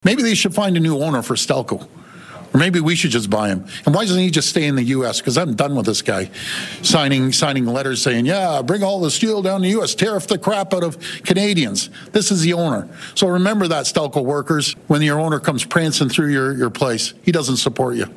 At a press conference in Hamilton on Wednesday, Ford criticized Lourenco Goncalves, president and CEO of Cleveland-Cliffs, after the executive publicly praised U.S. tariffs on steel under President Donald Trump.